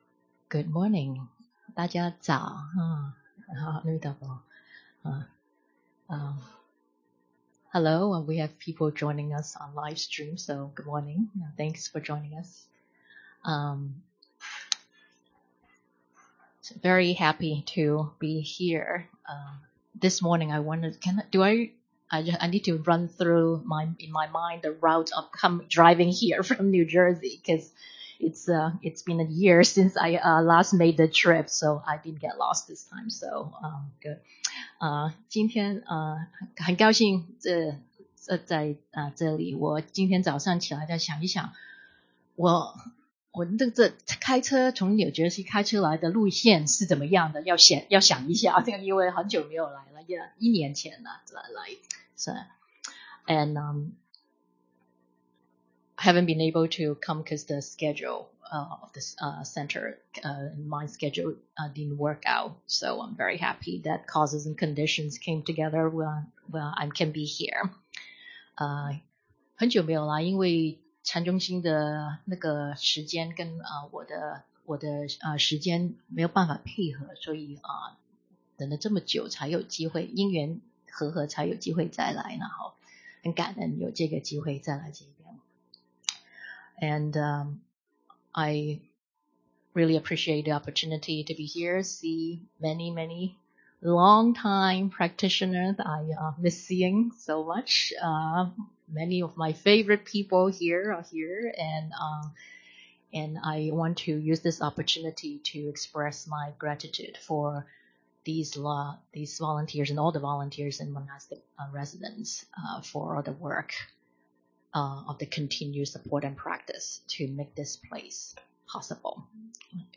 This bilingual talk was given at Chan Meditation Center on December 15, 2019.